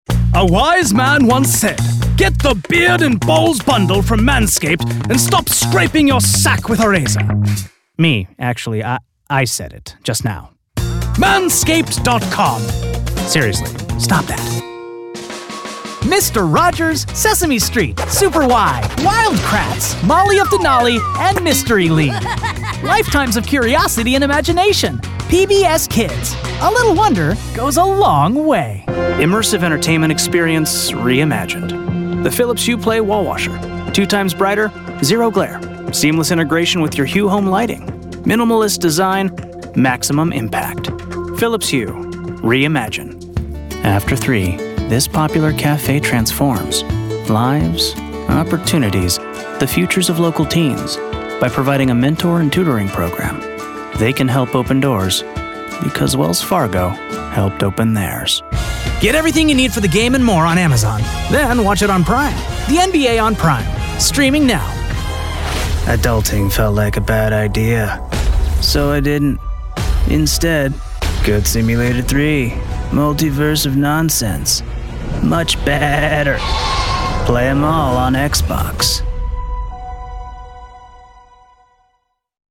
All our voice actors are premium seasoned professionals.
Yng Adult (18-29) | Adult (30-50)